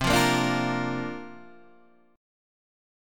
C 9th Suspended 4th